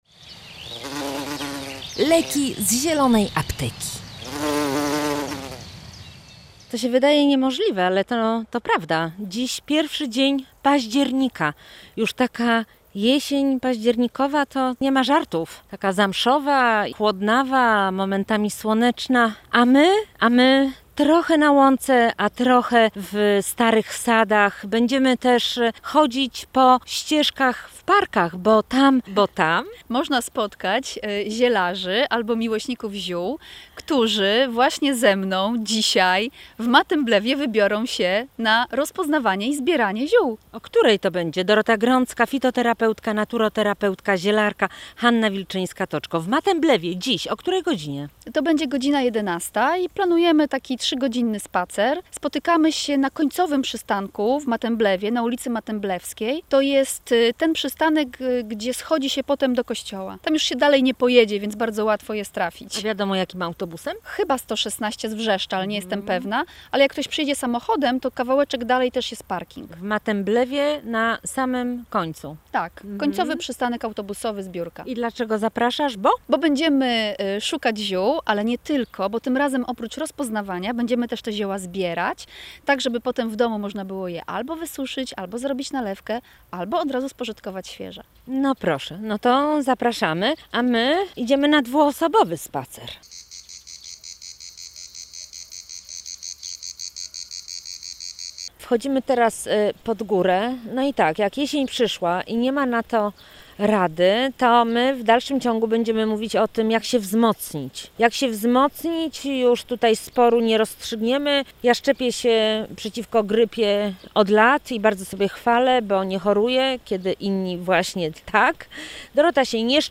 Panie rozmawiały także o łuszczycy – chorobie skóry, którą można wyleczyć odpowiednią dietą i ziołami.